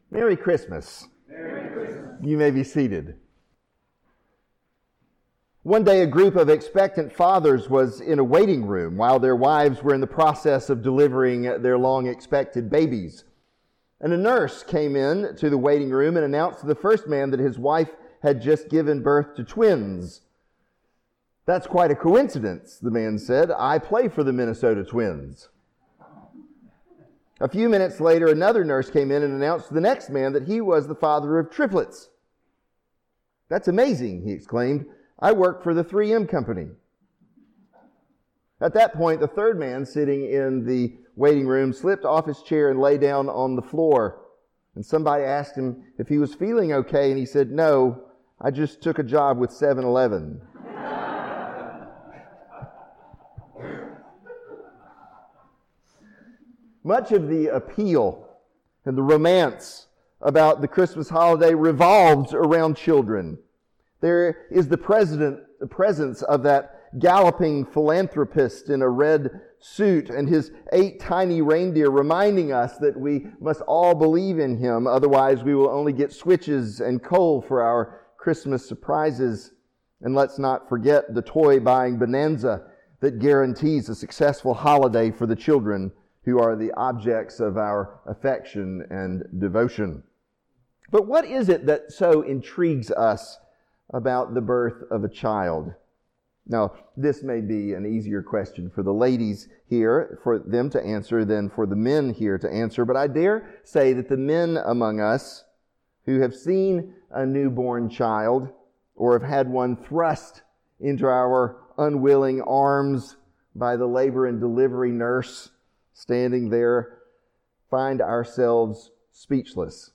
All Saints Church, Lynchburg, Virginia
Sermons